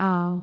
speech
syllable
pronunciation
aa6.wav